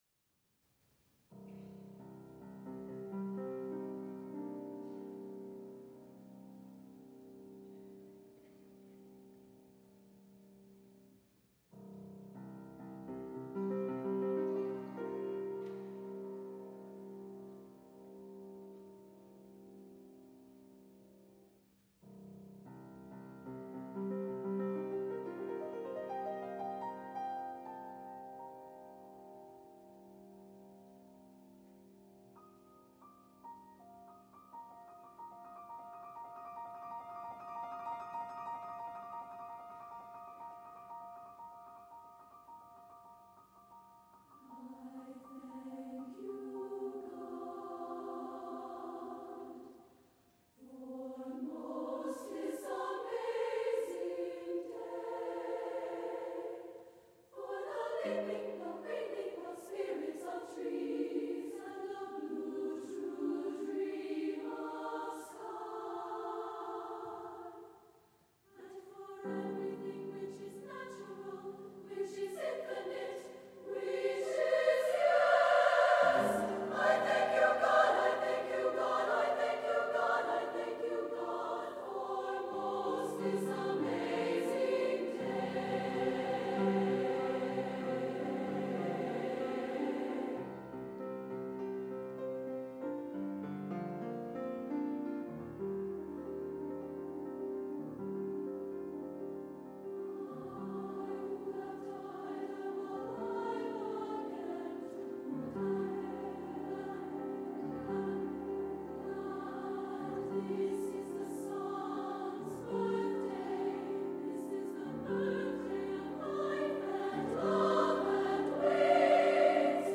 SSA and piano